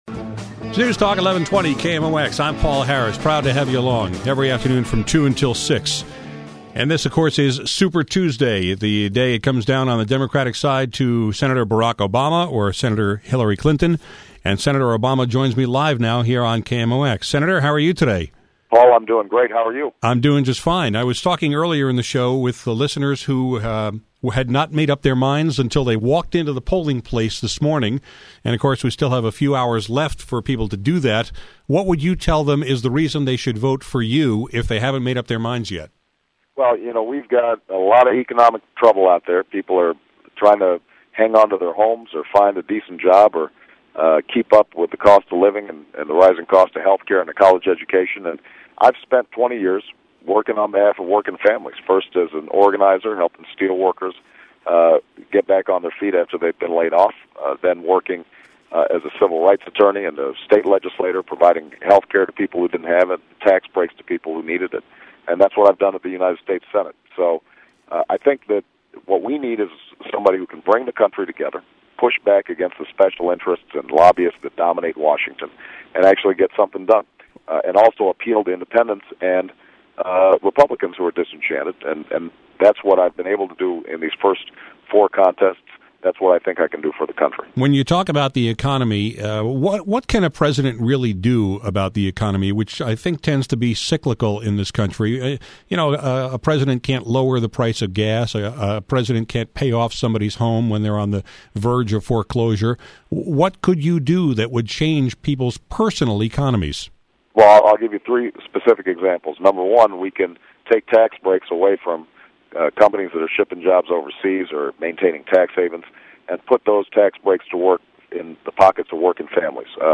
Today on my show, Senator Barack Obama surprised me by calling in for a few minutes to urge you to vote for him in the Super Tuesday primaries.